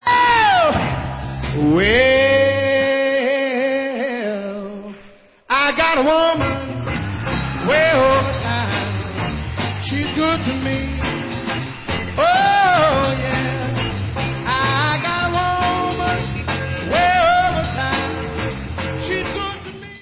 [Demo]